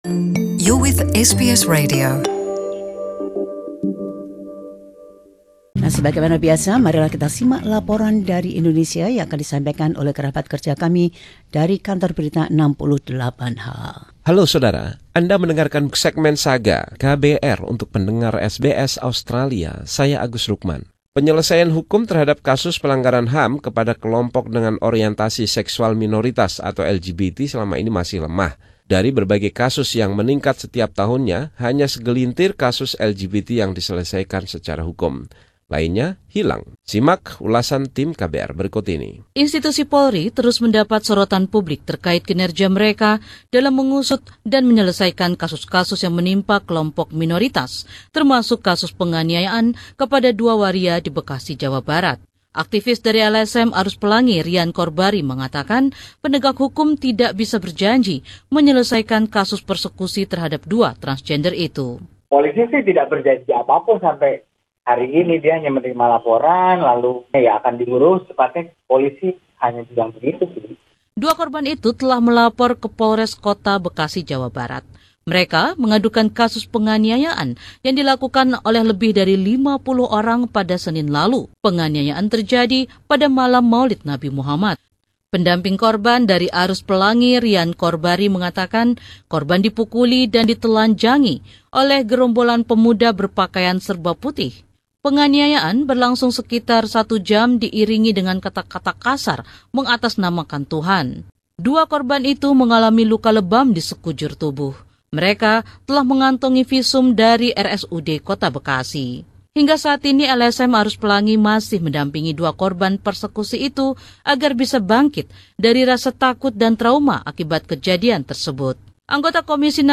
Laporan KBR 68H: Kekerasan terhadap Waria